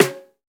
Snare